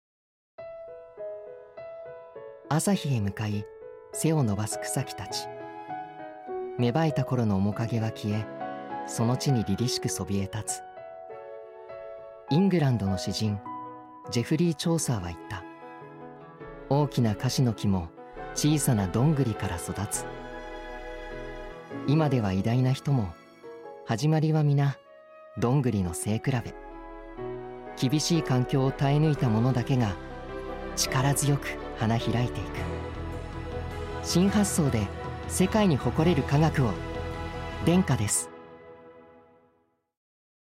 ラジオCM
denka_radiocm_tuesday.mp3